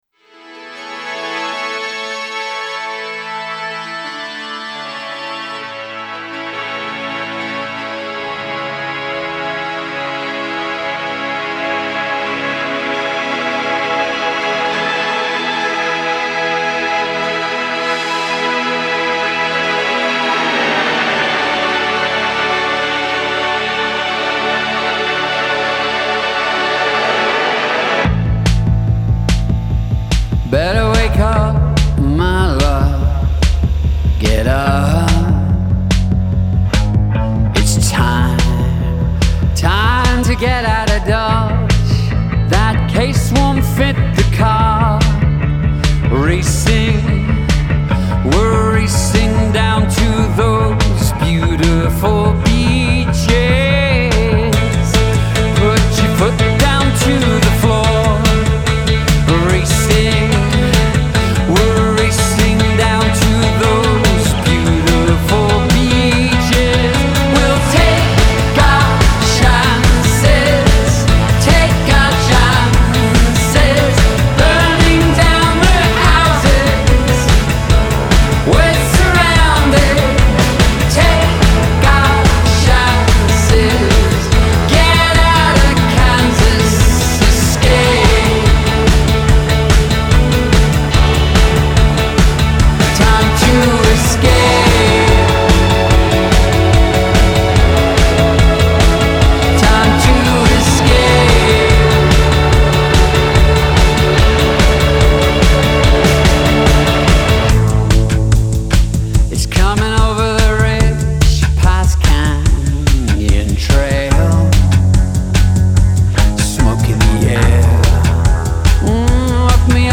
Genre : Alternative